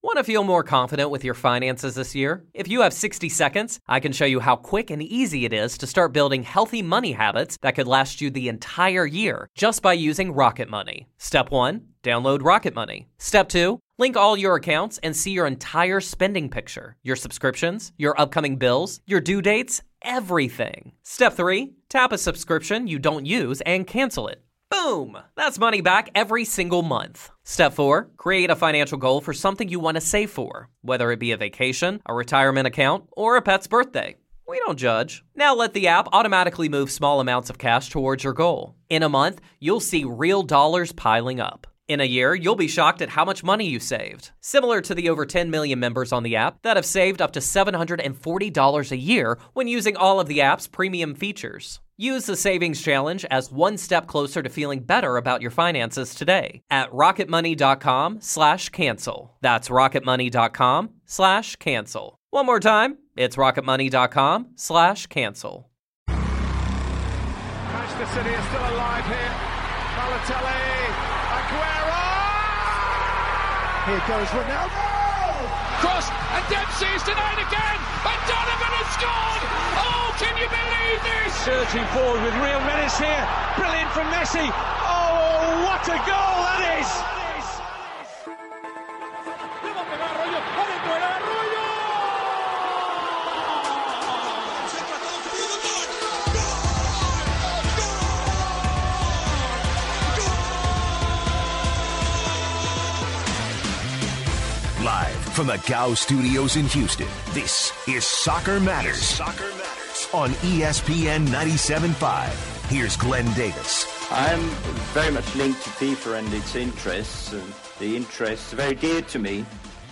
He takes calls from listeners on the future of Fifa moving forward.